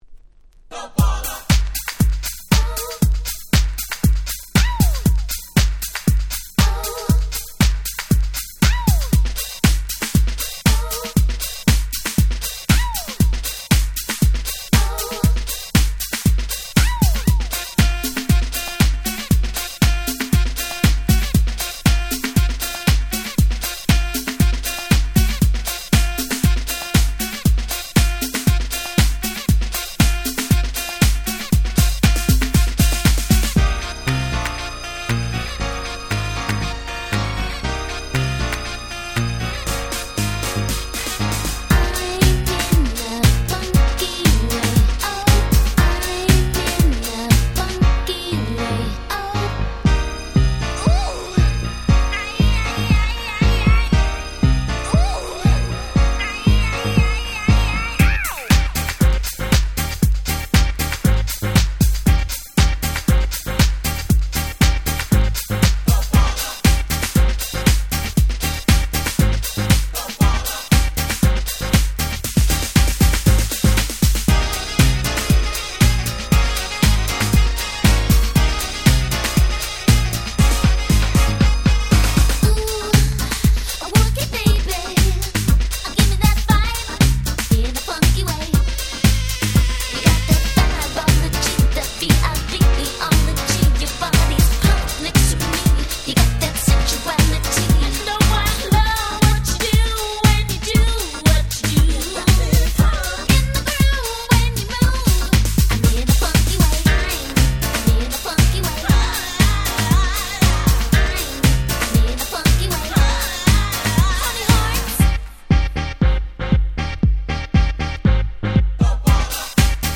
91' Super Hit R&B / Vocal House !!
Popでキャッチーな最高のDanceチューン！！